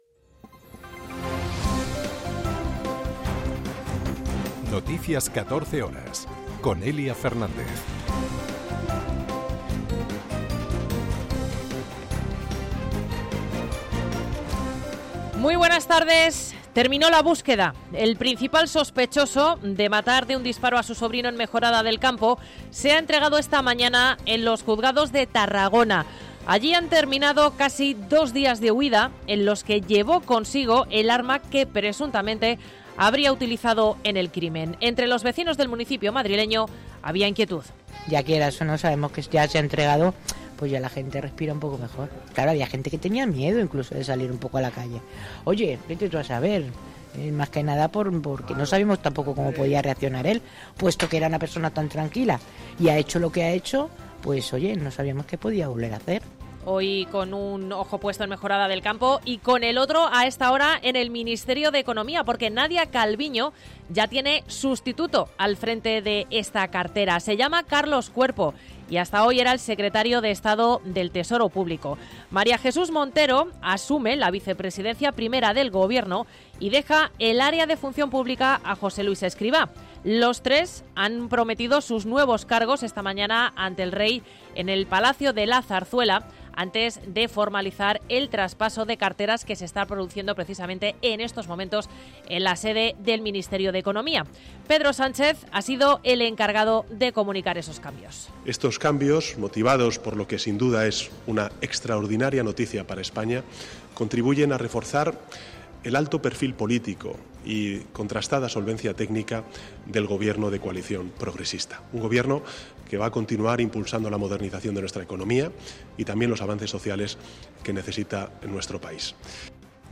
Noticias 14 horas 29.12.2023